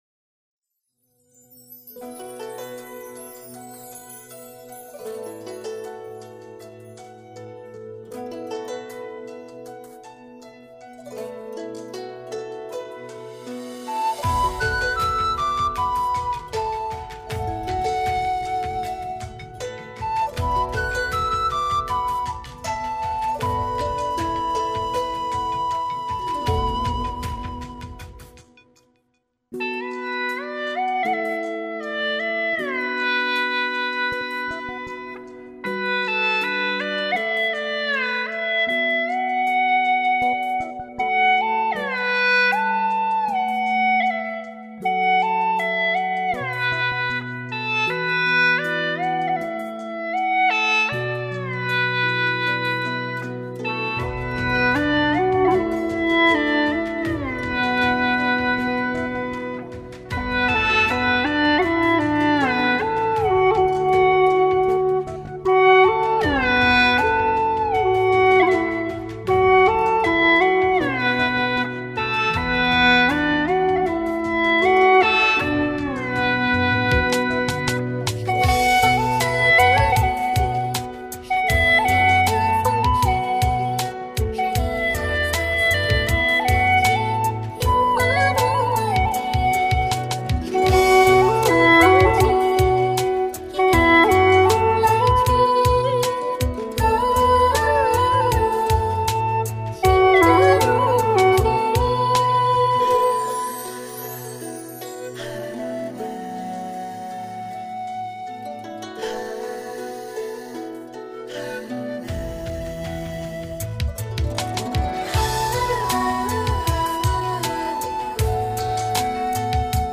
调式 : D 曲类 : 古风